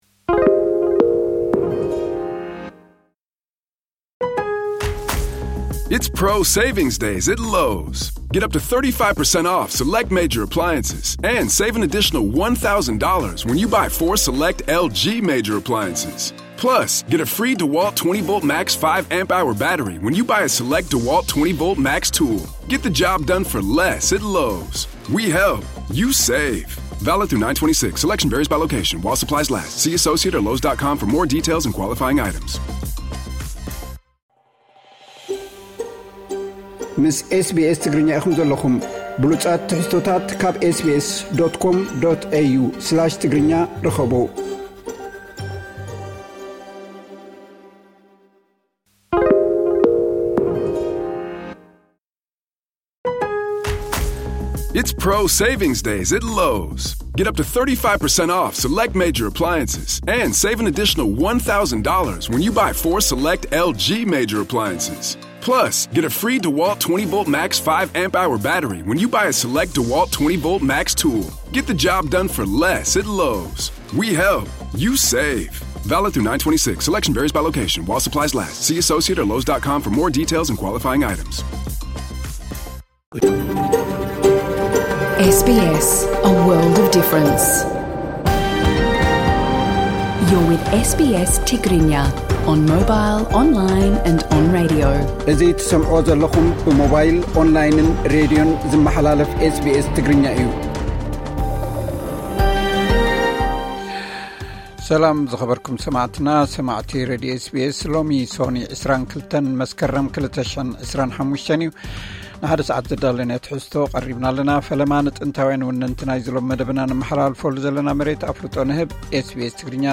ቀጥታ ምሉእ ትሕዝቶ ኤስ ቢ ኤስ ትግርኛ (22 መስከረም 2025)